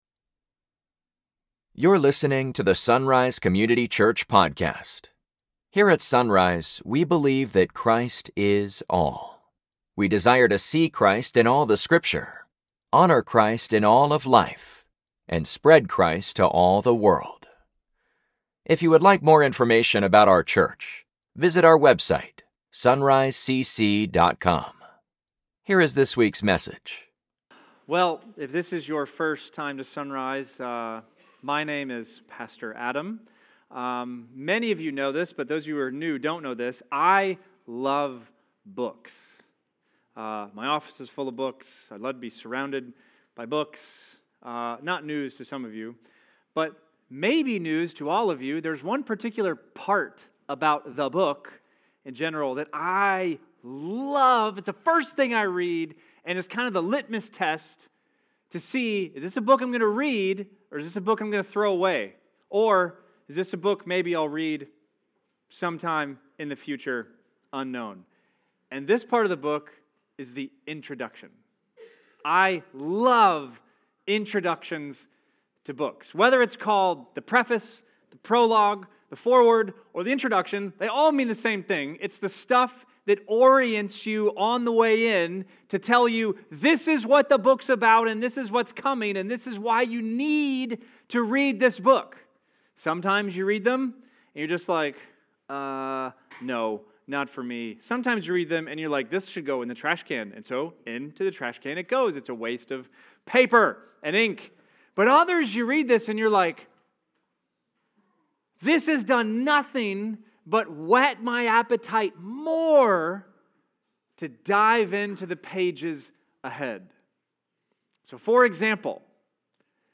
Sunday Mornings | SonRise Community Church